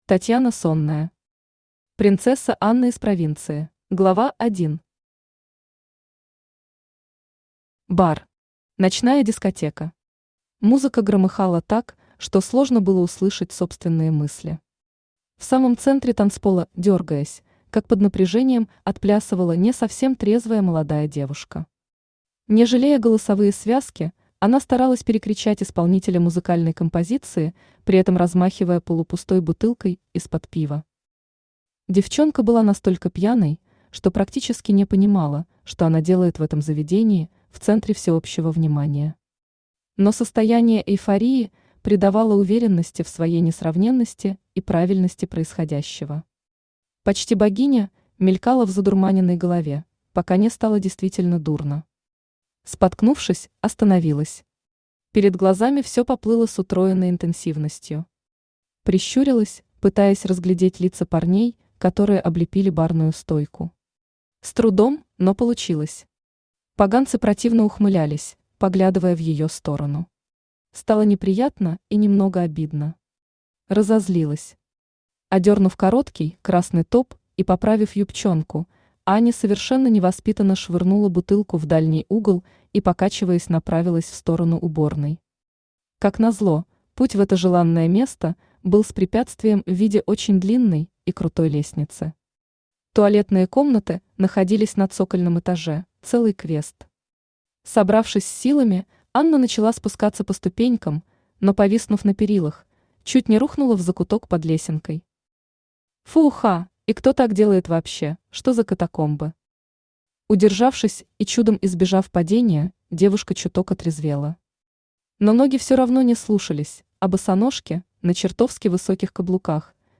Аудиокнига Принцесса Анна из Провинции | Библиотека аудиокниг
Aудиокнига Принцесса Анна из Провинции Автор Татьяна Сонная Читает аудиокнигу Авточтец ЛитРес.